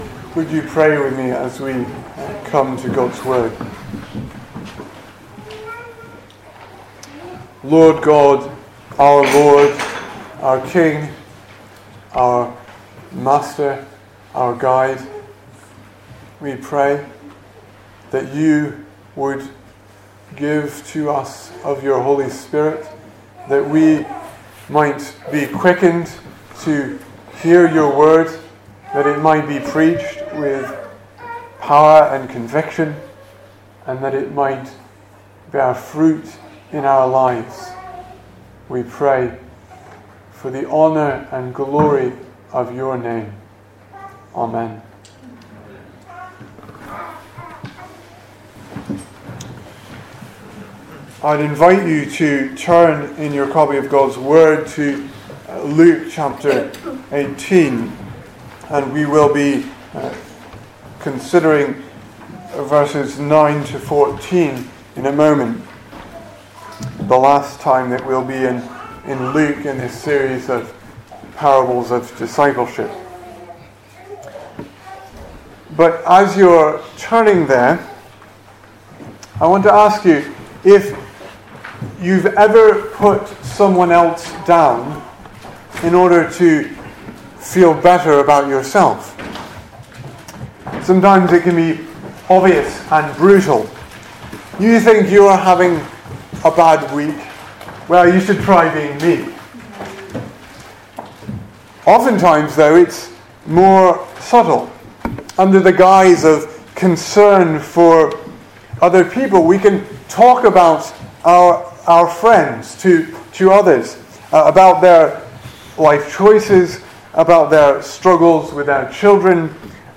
2018 Service Type: Sunday Morning Speaker